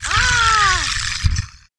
khanat-sounds-sources/sound_library/voices/death/haaaaaa/dr_die4.wav at f42778c8e2eadc6cdd107af5da90a2cc54fada4c
dr_die4.wav